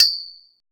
POLE PERC.wav